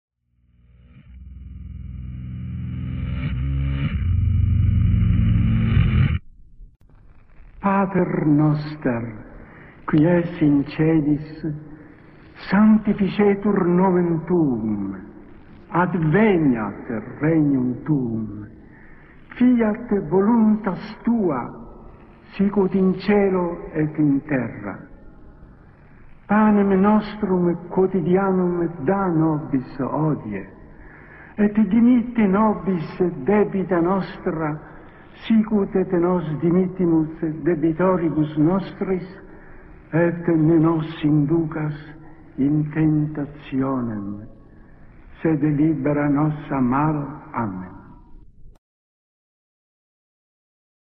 black metal
Il backmasking si trova all'inizio del brano.